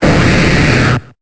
Cri de Léviator dans Pokémon Épée et Bouclier.